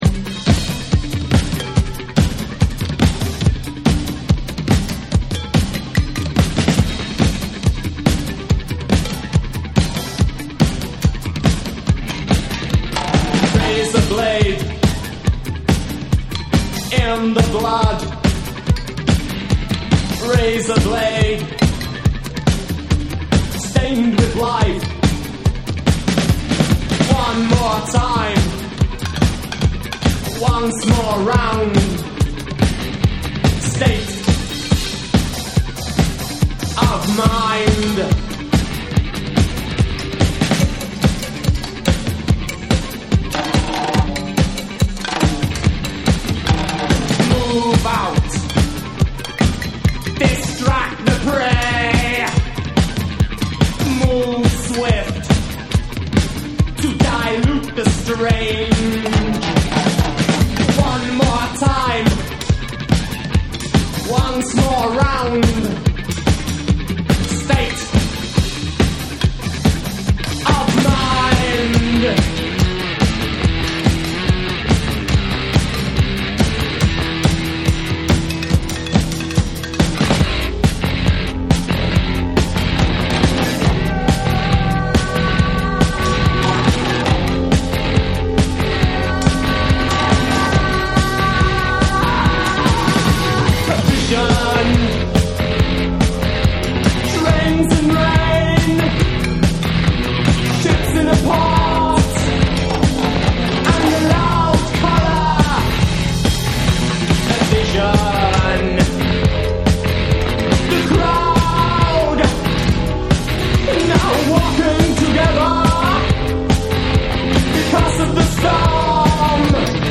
ポストパンク・バンド
4/4リズムのダンス・ミュージックとも相性抜群なインディーロック・ダンサー